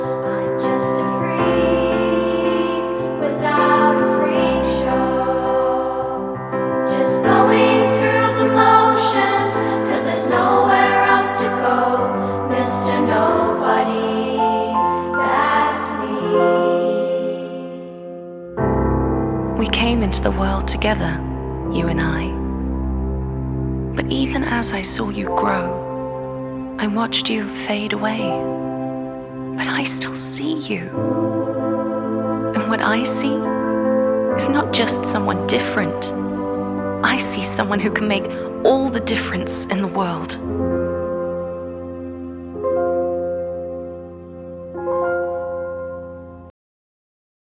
Momentum Narration
Momentum-Narration.wav